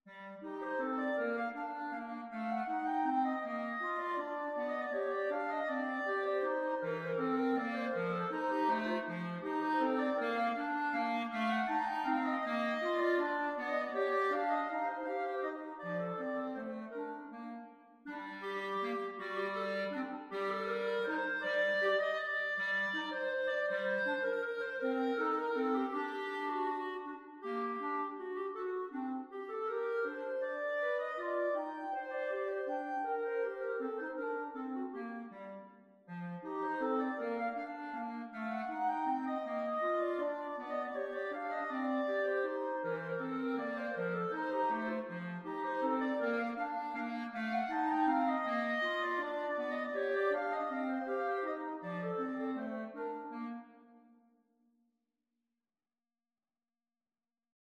Free Sheet music for Clarinet Duet
Clarinet 1Clarinet 2
3/4 (View more 3/4 Music)
Non troppo presto
Bb major (Sounding Pitch) C major (Clarinet in Bb) (View more Bb major Music for Clarinet Duet )
Classical (View more Classical Clarinet Duet Music)